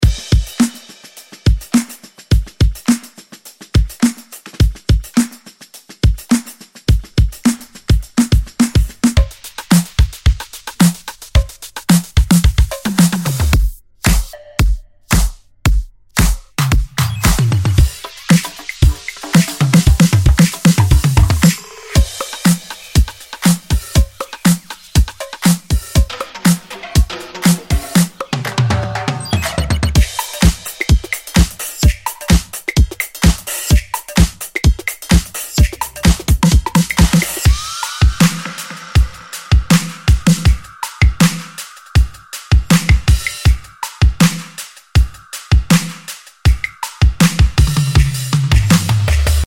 Funky drum loops + stems!! sound effects free download
Crafted with attention to detail and complete with fills and FX.